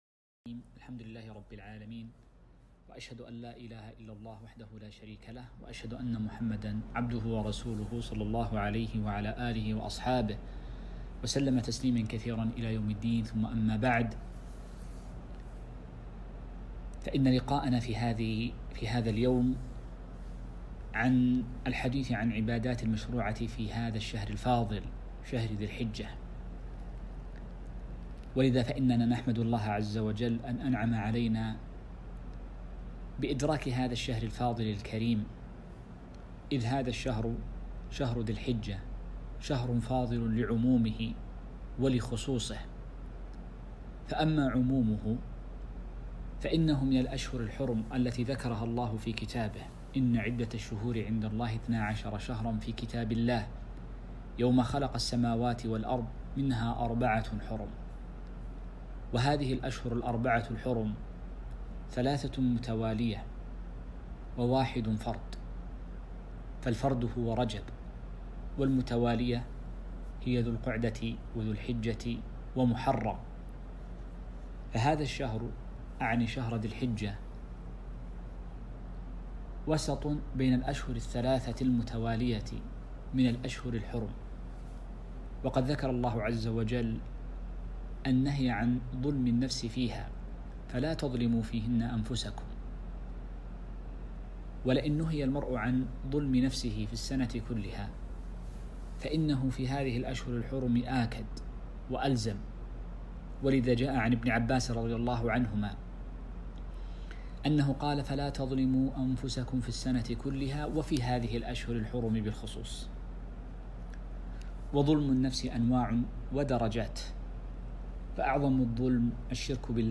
محاضرة - العبادات القولية في ذي الحجة